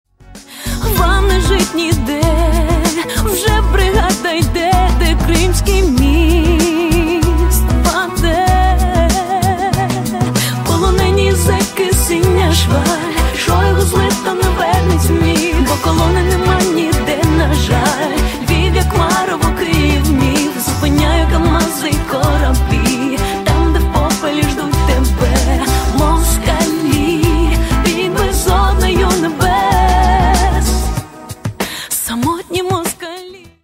• Качество: 128, Stereo
поп
женский вокал
кавер